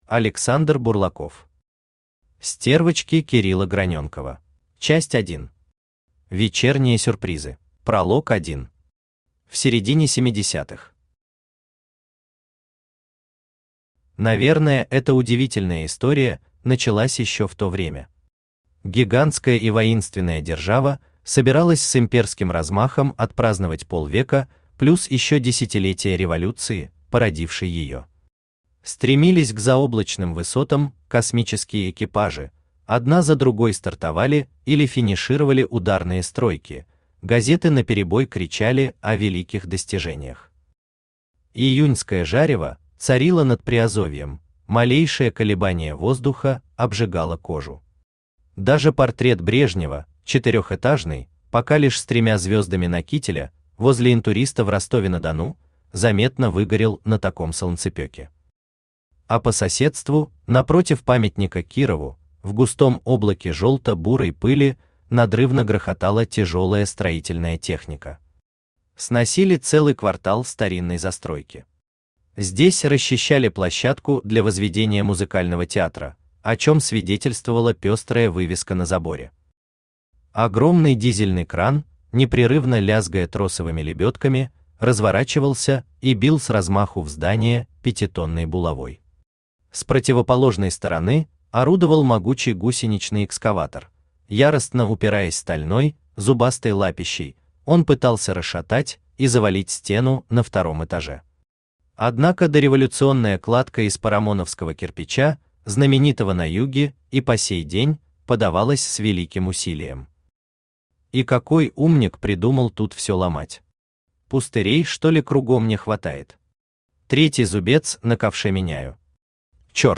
Аудиокнига Стервочки Кирилла Граненкова | Библиотека аудиокниг
Aудиокнига Стервочки Кирилла Граненкова Автор Александр Бурлаков Читает аудиокнигу Авточтец ЛитРес.